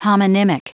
Prononciation du mot homonymic en anglais (fichier audio)
Prononciation du mot : homonymic